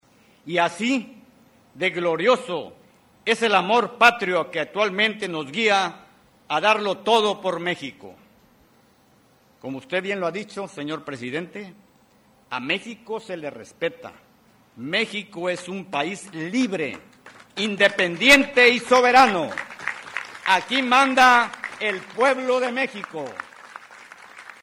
En la ceremonia realizada en la Macro Plaza del Puerto de Veracruz, el Almirante Ojeda Duran dijo que en Memoria de los héroes del 21 de abril de 1914 refrendo el compromiso de la Marina con México.